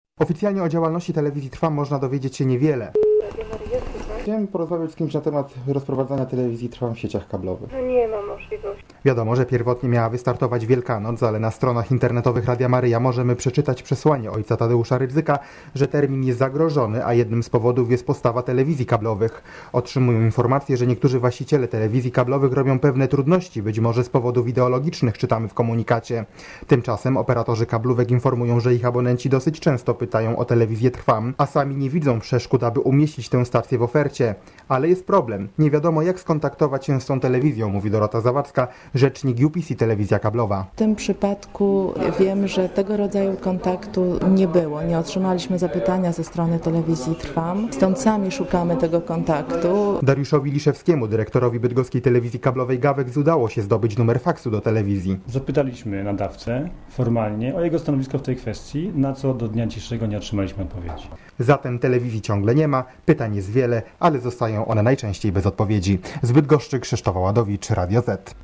Co na to operatorzy kablowi? Sprawdzał Reporter Radia Zet (535Kb)